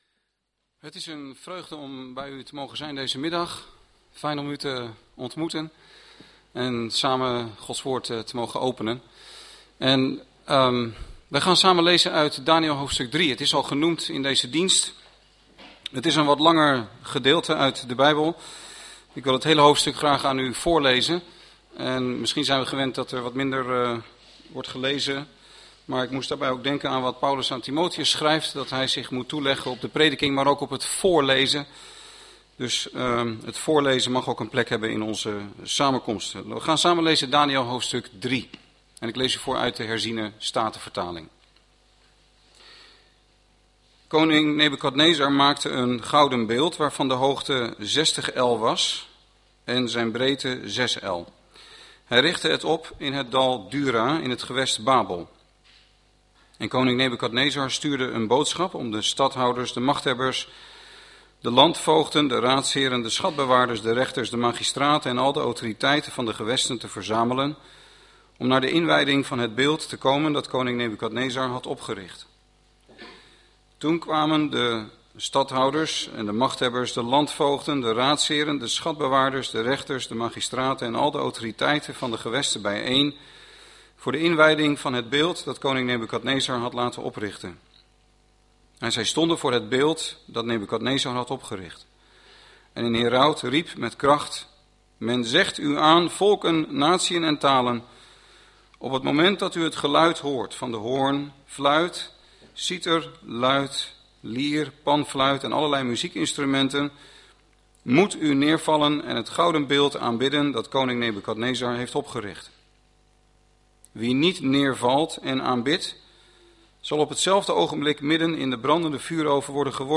preek3.mp3